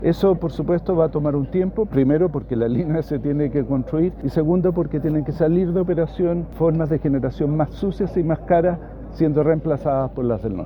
Ceremonia de inicio de obras del megaproyecto Kimal-Lo Aguirre
Pero no solo se trata de mejor infraestructura, puestos de trabajo y energías limpias, sino también de un alivio futuro para las familias, como expuso el biministro de Economía y Energía, Álvaro García.